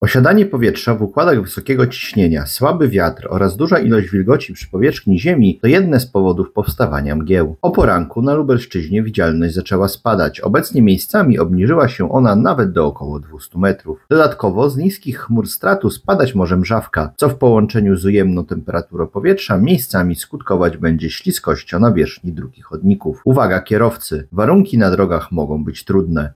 O szczegółach mówi dyżurny